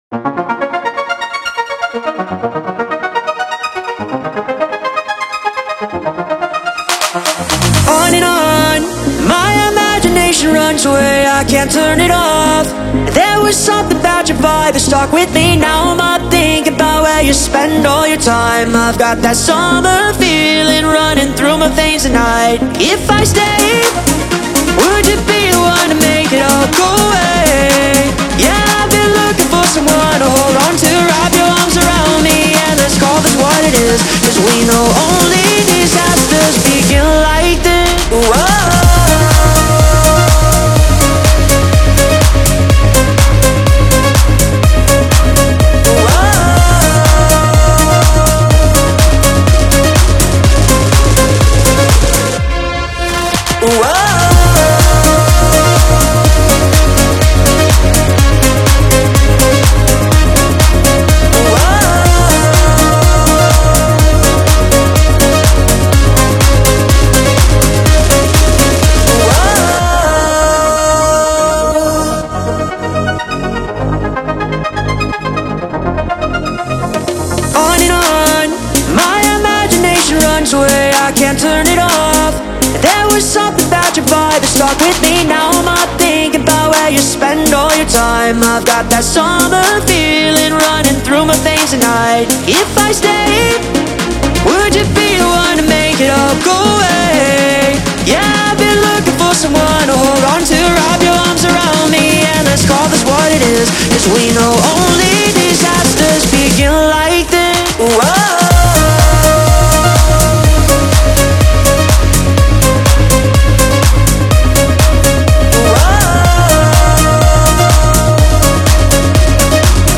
• Жанр: Dance, Pop